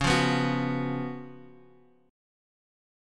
Db11 Chord
Listen to Db11 strummed